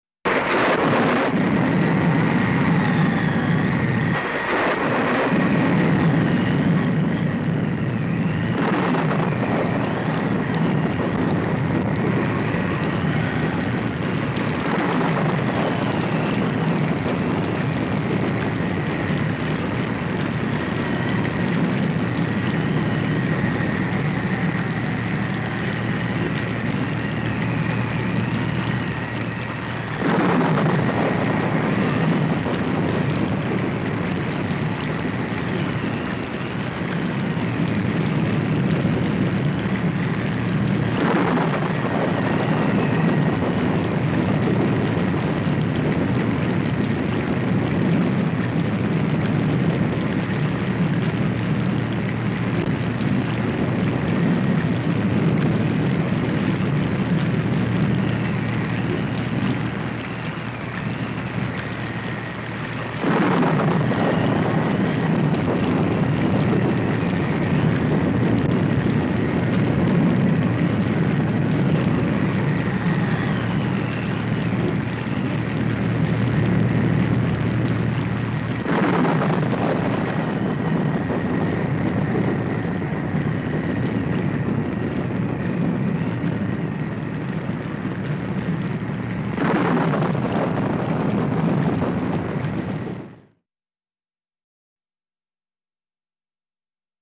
Громовые раскаты, завывание ветра и шум ливня создают эффект присутствия.
Звуки бури, грозы, мощного ветра и ливня